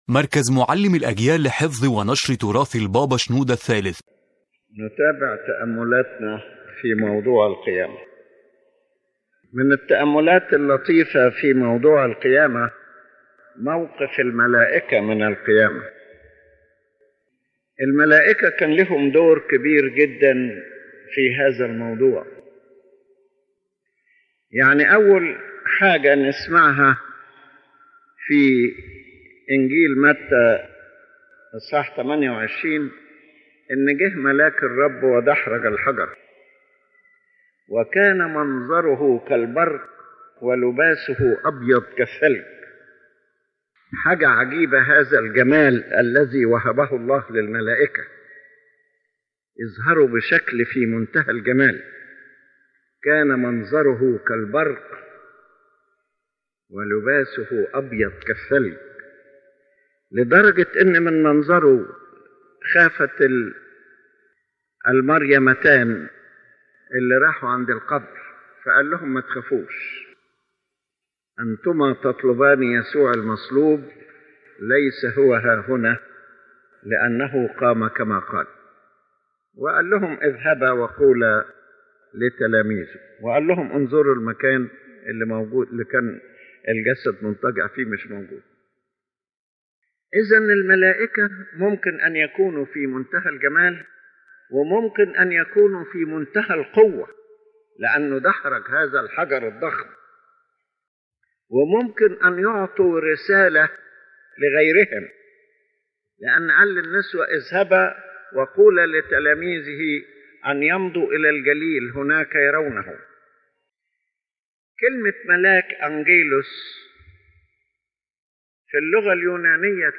The lecture contemplates the great role played by the angels in the event of the Resurrection of the Lord Christ.